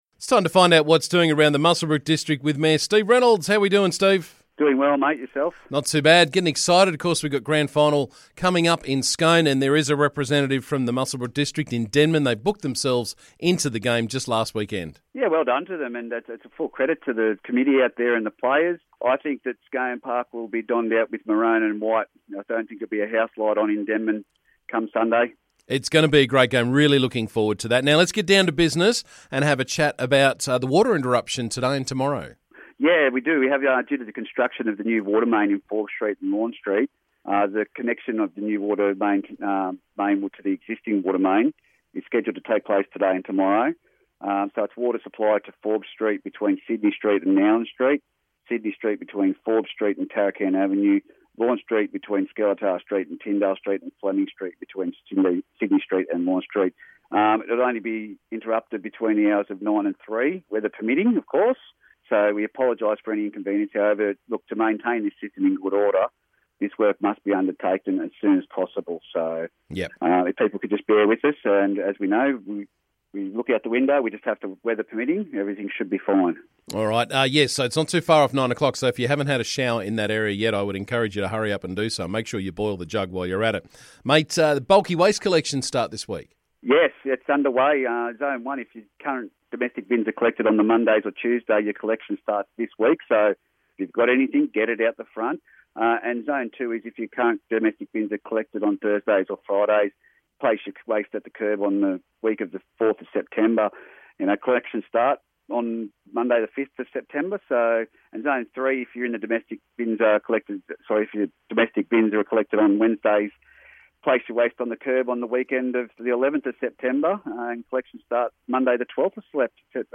Muswellbrook Shire Council Mayor Steve Reynolds was on the show this morning with the latest from around the district.